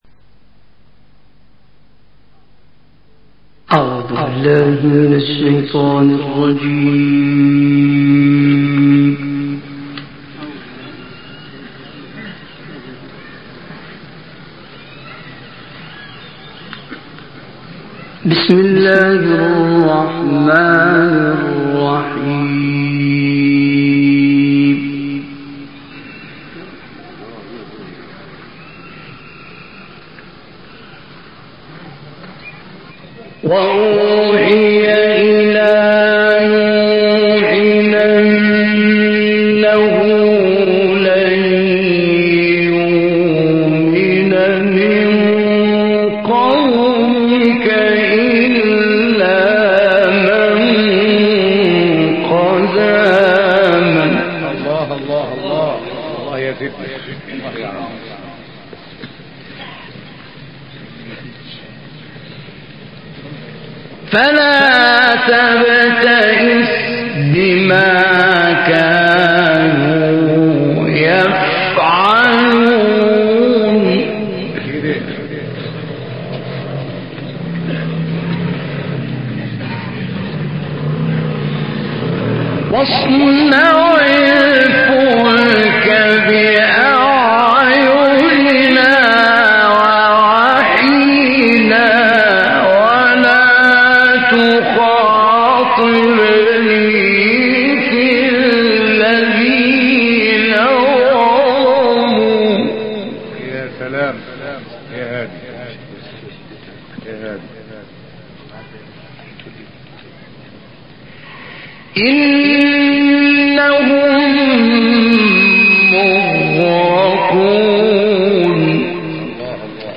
دانلود قرائت سوره هود آیات 36 تا 57 و 69 تا 73 - استاد حمدی محمود زامل